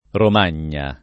Romagna [ rom # n’n’a ]